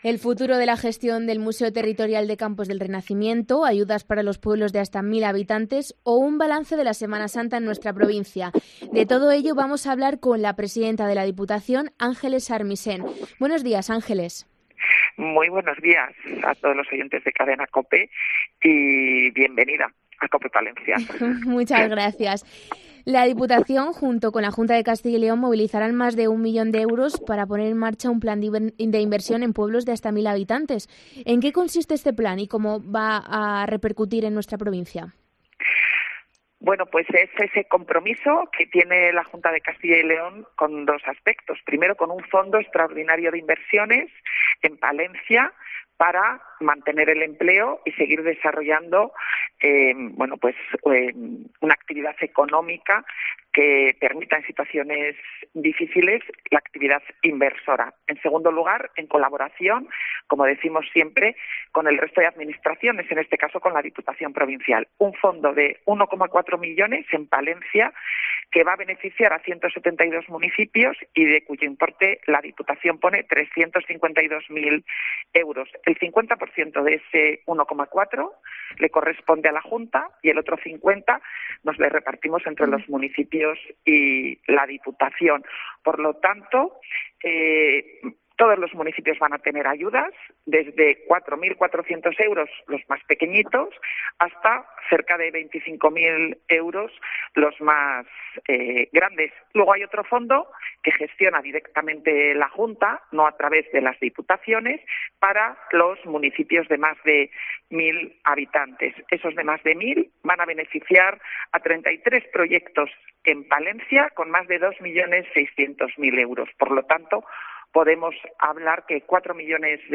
Entrevista a la presidenta de la Diputación de Palencia, Ángeles Armisén